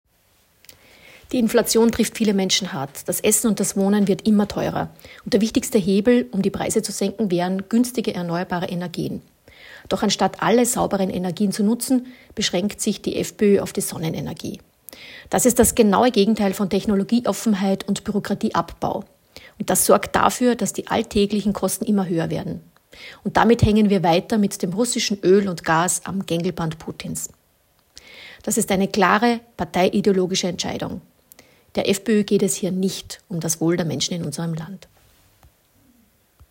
O-Ton Martina Berthold